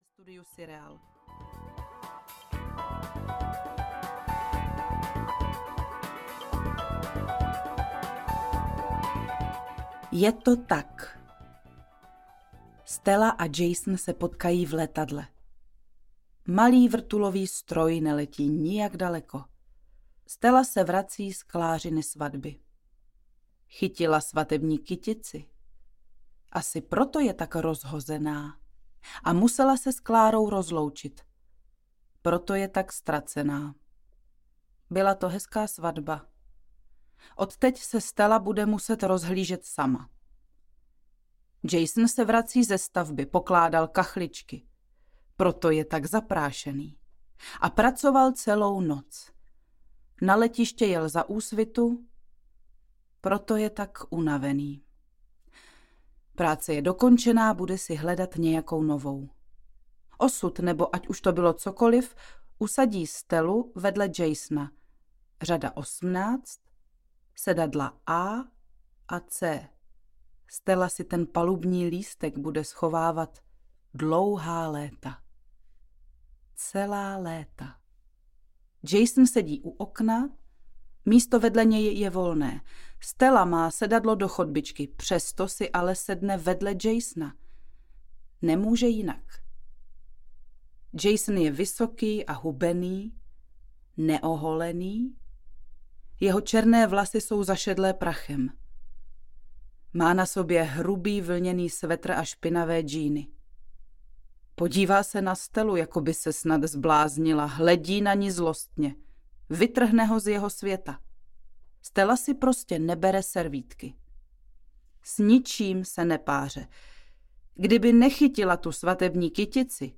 Počátek veškeré lásky audiokniha
Ukázka z knihy